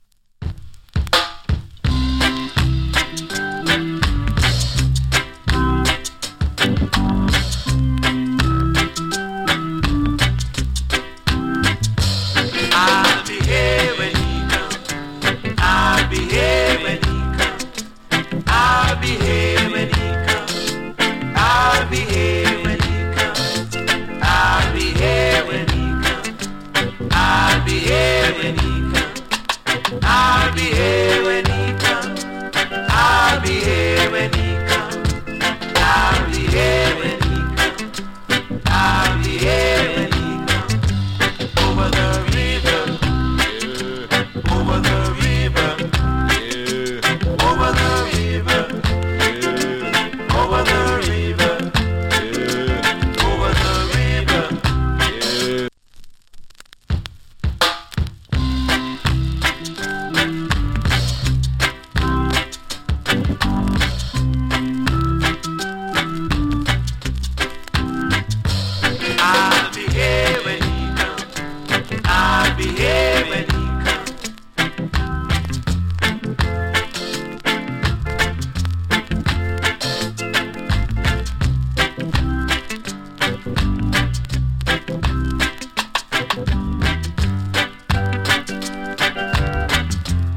A：VG(OK) / B：VG(OK) NOC. ＊スリキズ少々有り。チリ、ジリノイズ少々有り。
EARLY REGGAE STYLE !